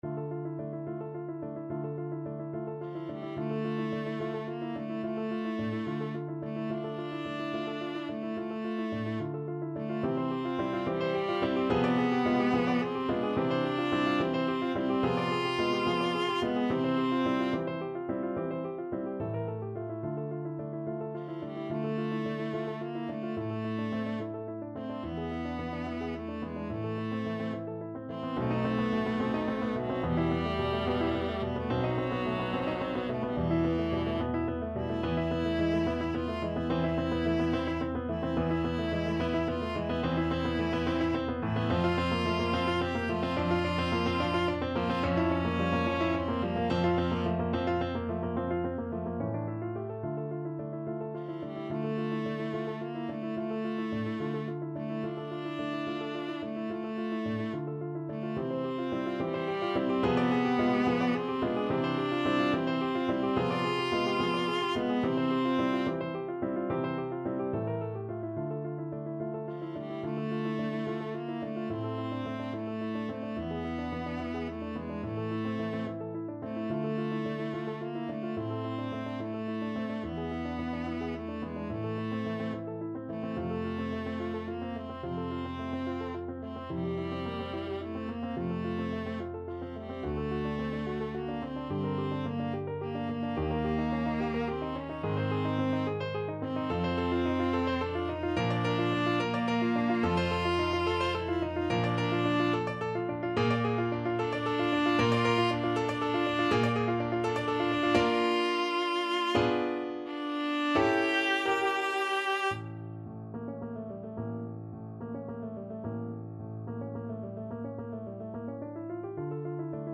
Classical
Viola version